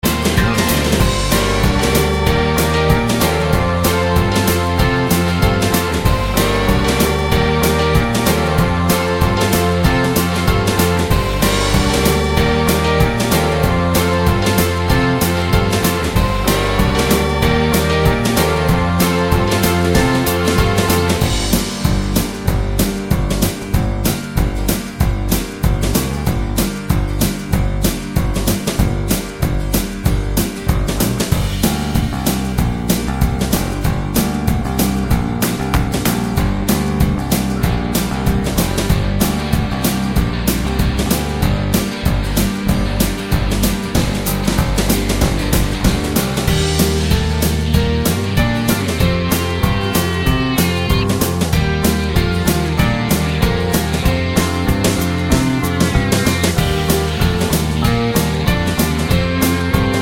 Pop (2020s) 2:50 Buy £1.50